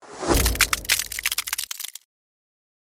Free SFX sound effect: Ice Magic Bolt.
Ice Magic Bolt
yt_NLirModTEoc_ice_magic_bolt.mp3